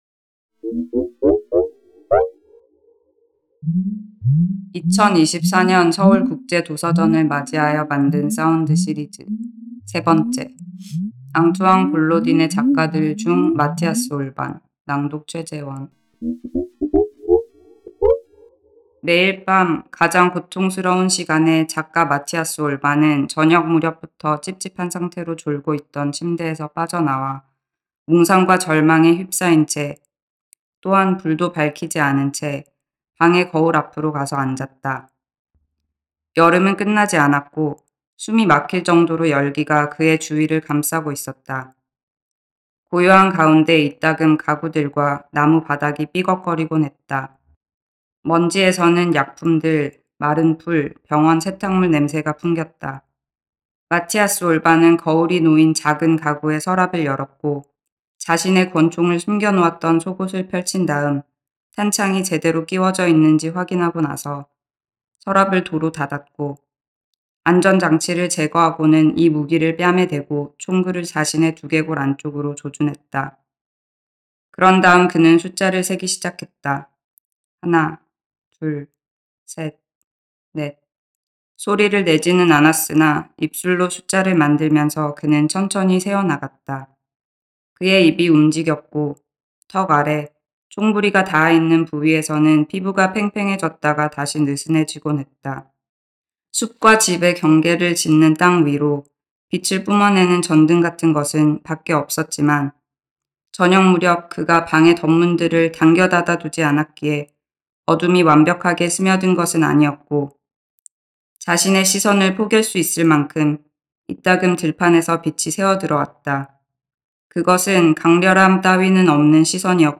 앙투안 볼로딘의 단편소설집 『작가들』(조재룡 옮김) 중 첫 번째 작품 「마티아스 올반」의 일부를 읽어 봅니다.